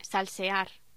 Locución: Salsear
voz
Sonidos: Voz humana